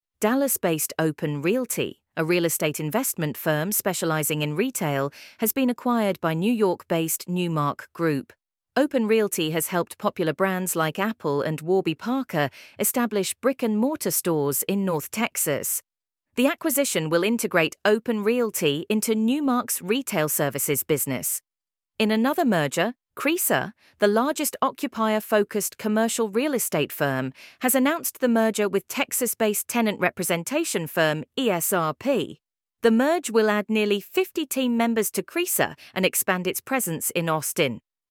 Quick Summary Audio